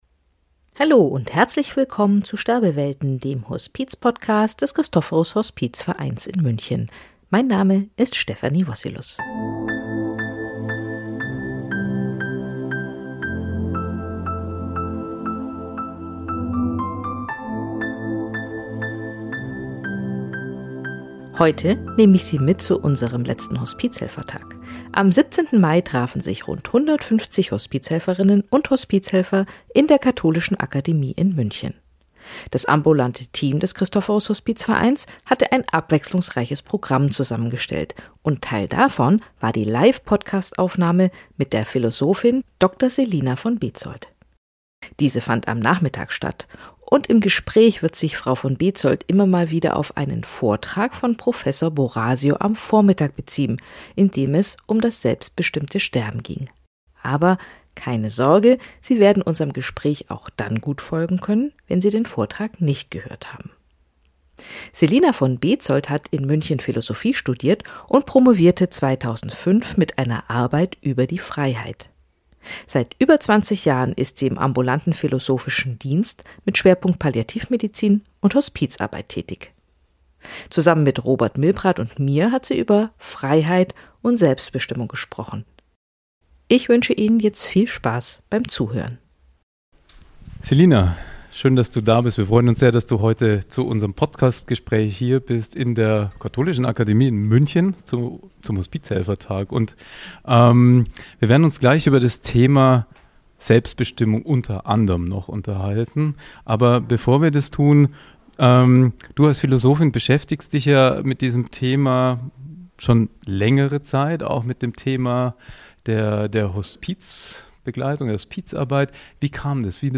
die Folge wurde live aufgezeichnet